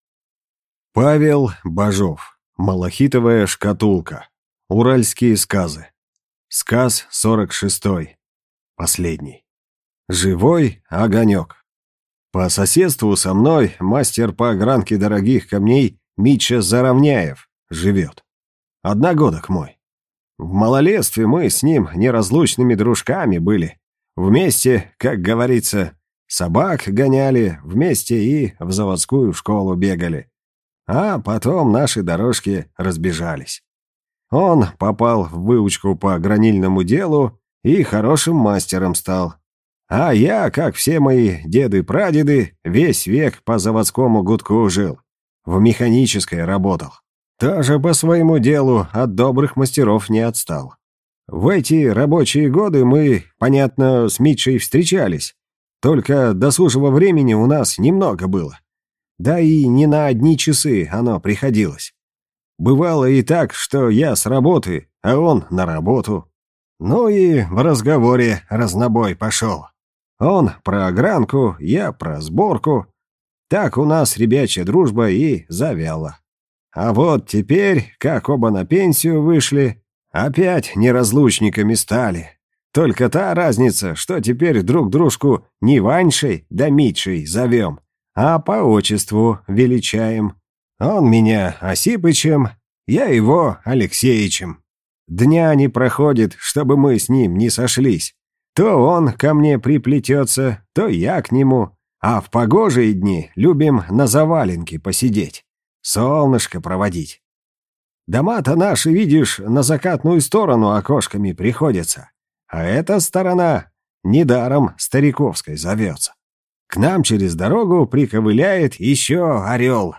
Аудиокнига Живой огонек | Библиотека аудиокниг